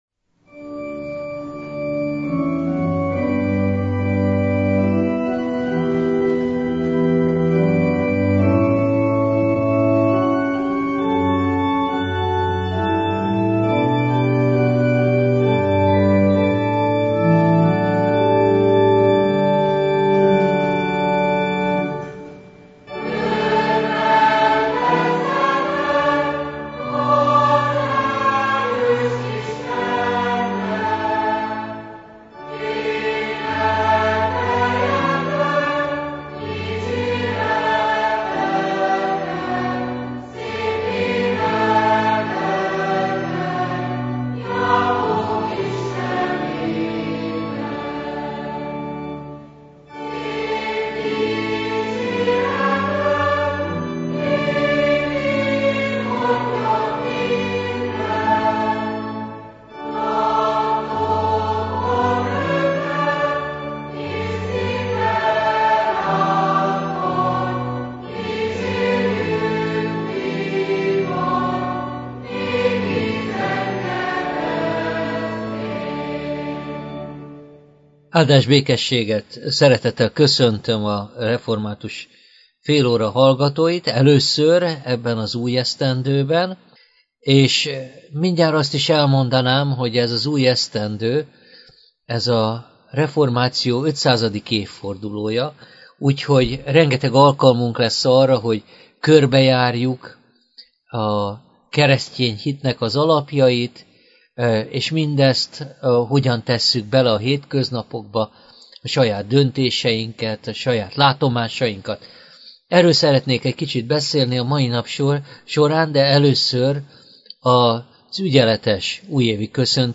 Igét hirdet